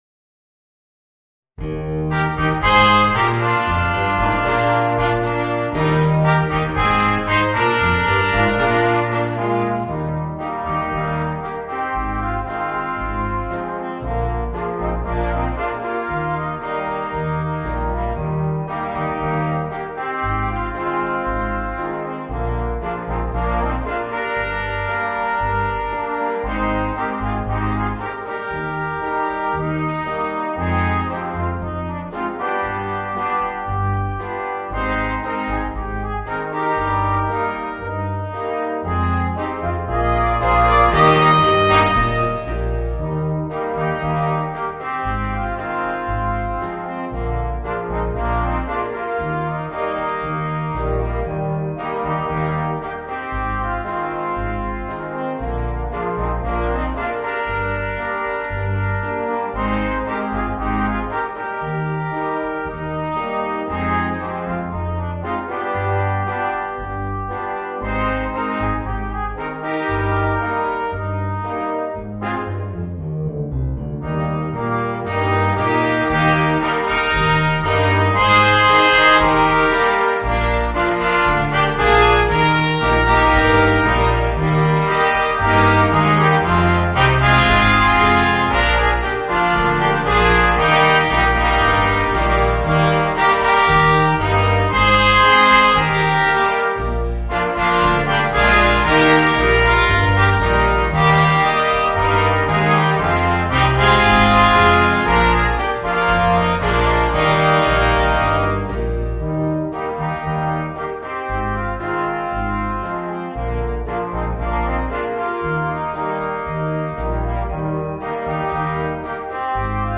Voicing: 3 Trumpets, Horn and Trombone